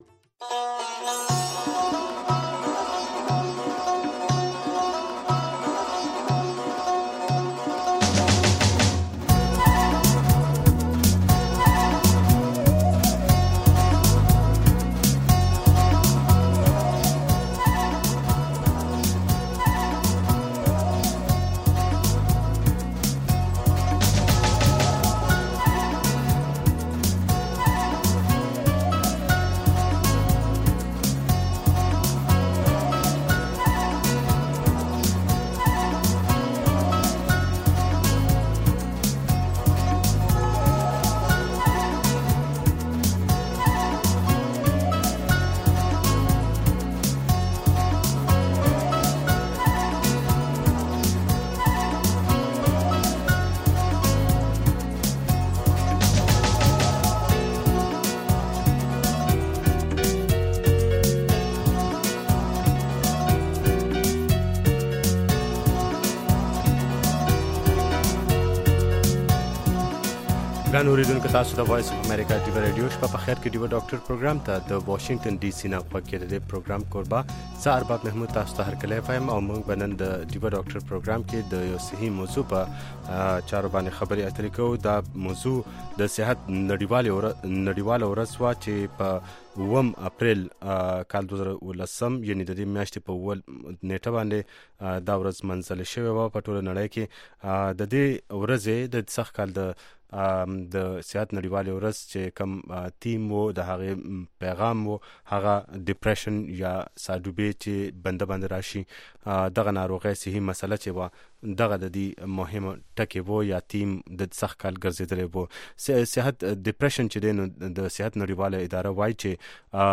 د شپې ناوخته دا پروگرام د سټرو اوریدنکو لپاره ښائسته خبرې او سندرې هم لري.